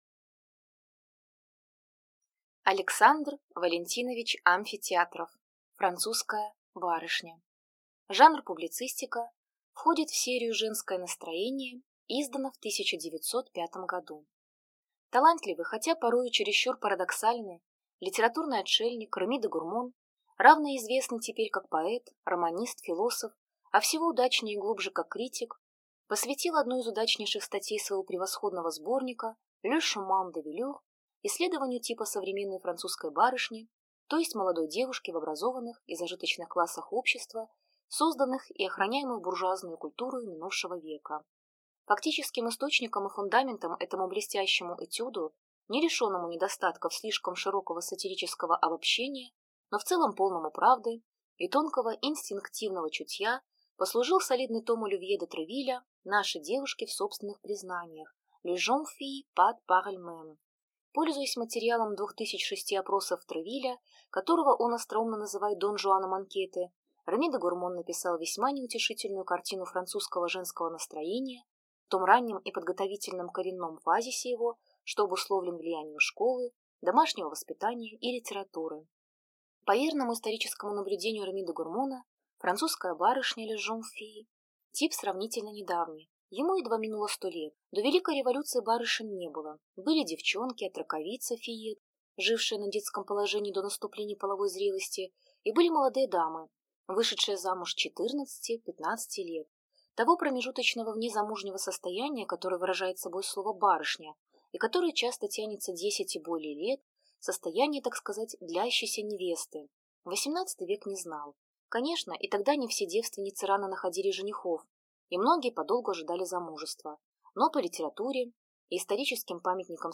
Аудиокнига Французская барышня | Библиотека аудиокниг